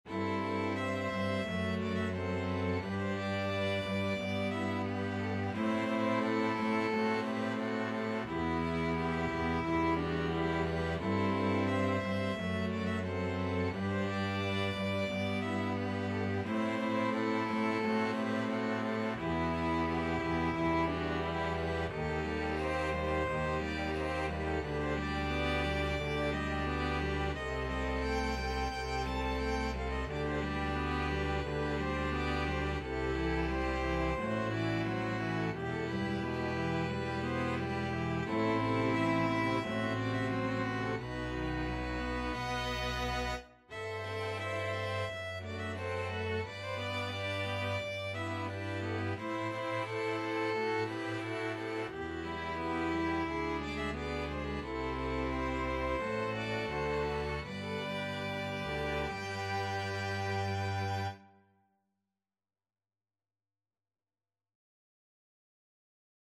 Free Sheet music for String Ensemble
Violin 1Violin 2ViolaCelloDouble Bass
4/4 (View more 4/4 Music)
G major (Sounding Pitch) (View more G major Music for String Ensemble )
Molto allegro =176
Classical (View more Classical String Ensemble Music)
borodin_polovtsian_STRE.mp3